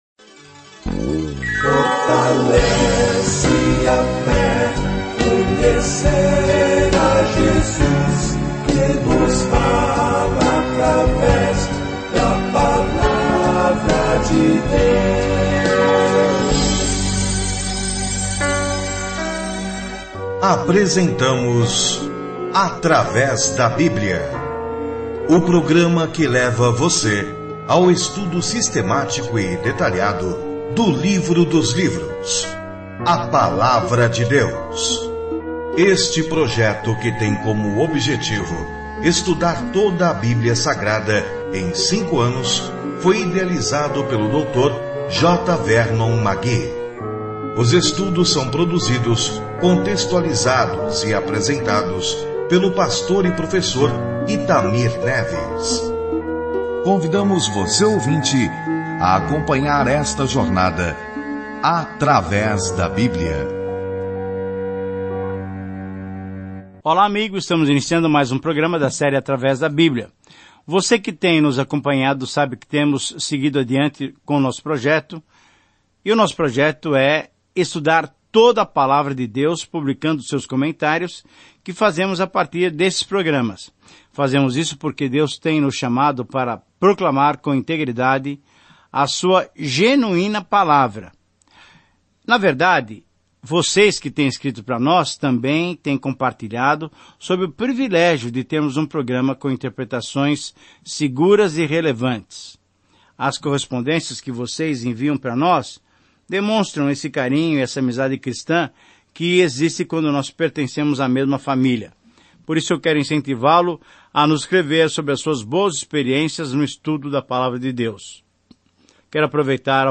As Escrituras Provérbios 27:1-27 Dia 27 Começar esse Plano Dia 29 Sobre este Plano Provérbios são frases curtas extraídas de longas experiências que ensinam a verdade de uma forma fácil de lembrar - verdades que nos ajudam a tomar decisões sábias. Viaje diariamente por Provérbios enquanto ouve o estudo de áudio e lê versículos selecionados da palavra de Deus.